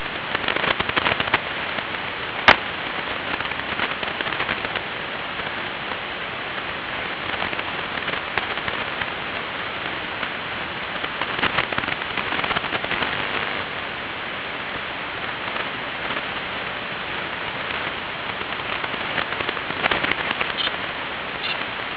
DECAMETRIC RADIO EMISSIONS
In 1955, astronomers Bernard Burke and Kenneth Franklin detected radio emissions coming from the planet Jupiter, radio emissions with wavelengths long enough to be measured in decameters.